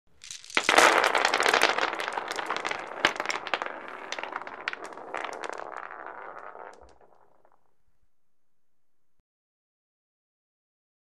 Marbles Spill And Roll On Wooden Floor